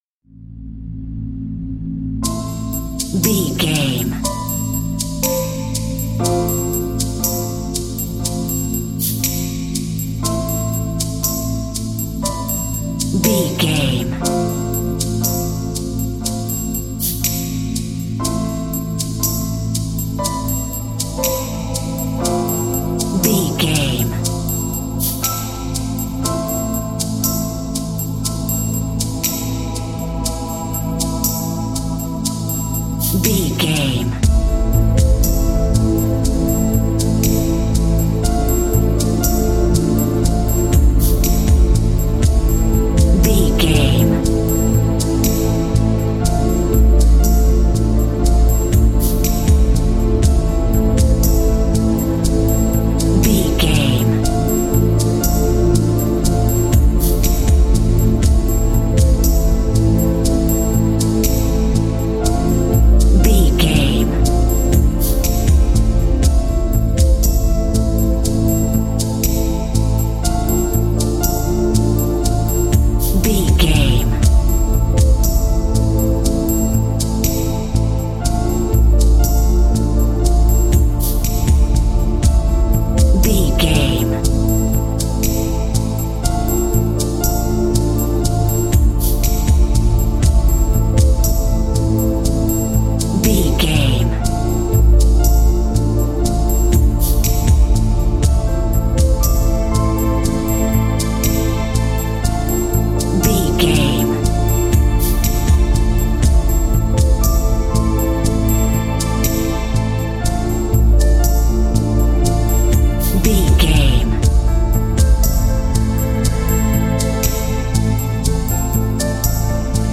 Aeolian/Minor
Slow
synthesiser
piano
percussion
drum machine
ominous
dark
suspense
haunting
tense
creepy